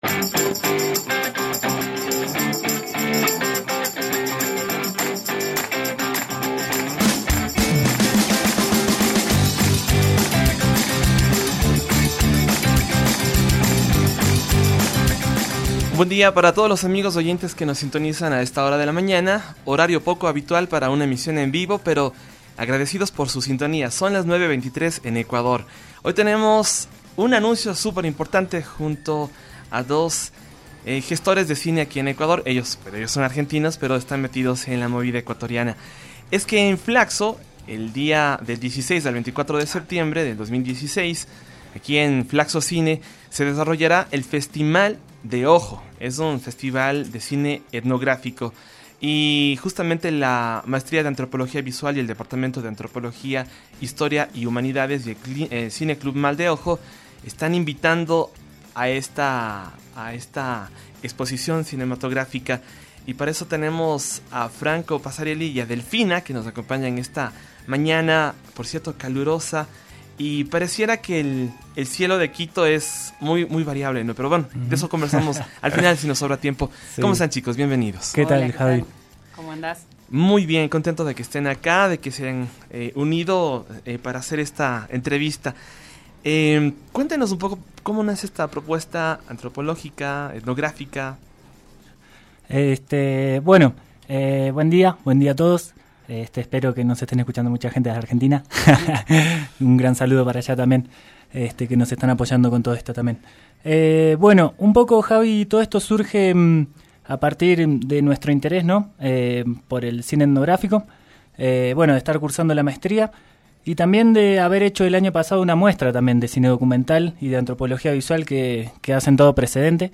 Películas de África, Europa del Este, y América están en la nueva edición del Festimal de Ojo, evento con el respaldo de FLACSO Ecuador, su Maestría en Antropología Visual del Departamento de Antropología, Historia y Humanidades, el cual se realiza desde el 16 al 24 de septiembre de 2016, en las instalaciones de FLACSO Cine (Calles San Salvador E7-42 y La Pradera). Compartimos con ustedes detalles de este evento en la siguiente entrevista